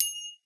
指形镲片 " 指形镲片侧噎02
描述：这个包包含了指钹的声音样本。包括了一起撞击时的击打和窒息，以及从边缘一起撞击时的声音。还有一些效果。
Tag: 指钹 管弦乐 打击乐